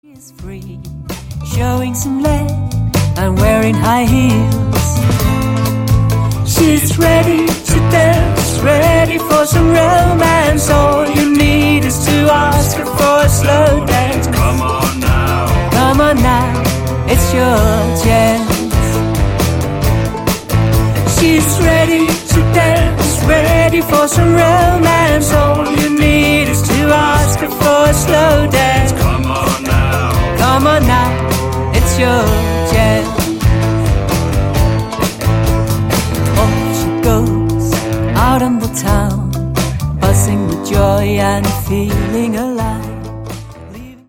• Blues
• Folk
• Pop
• Nordisk americana
Guitar
Vokal
Duokoncert med guitar, sang, mundharpe